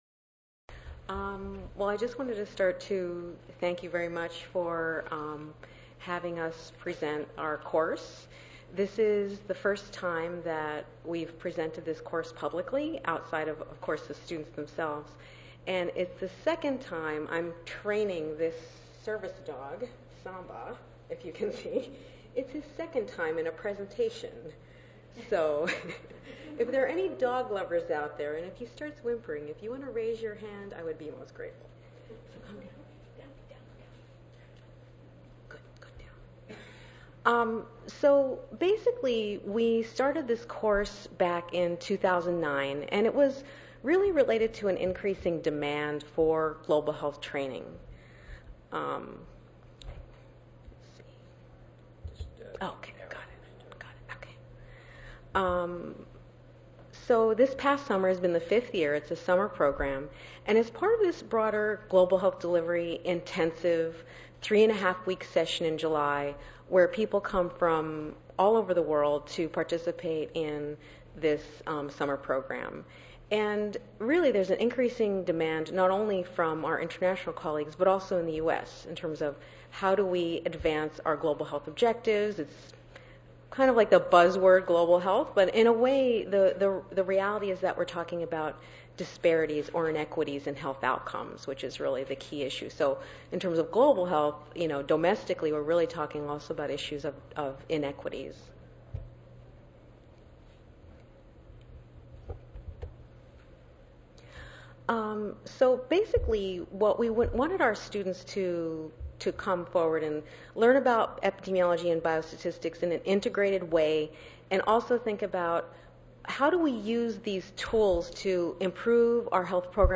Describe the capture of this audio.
141st APHA Annual Meeting and Exposition (November 2 - November 6, 2013): Advances in methods and content for education in global health epidemiology